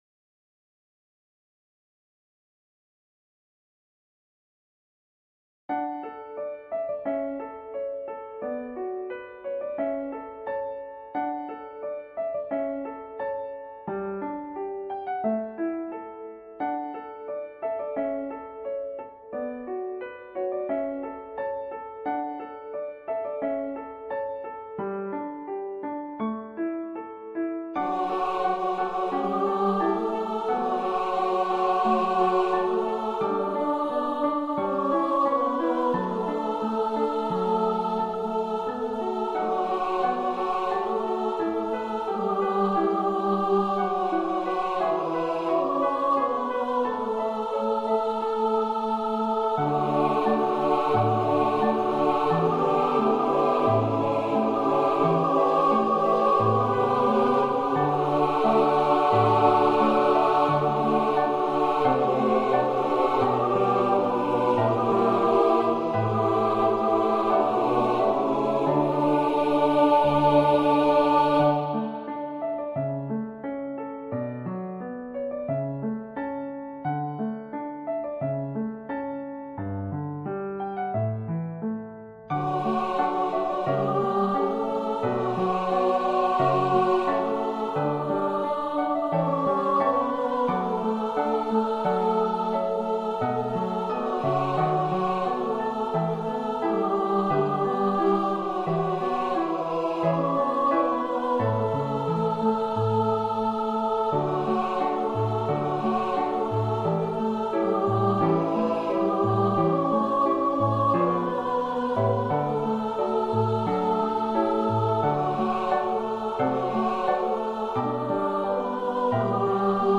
Choir & Piano) Arrangement